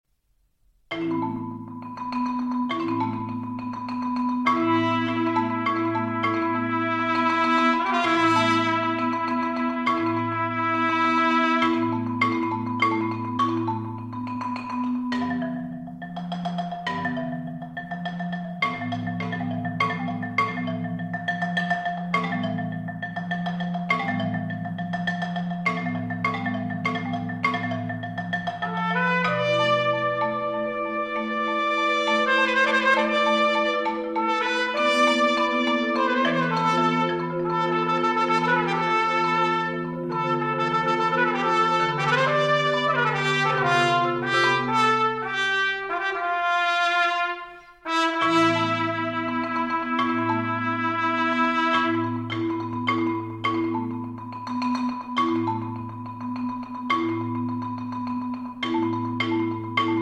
trumpets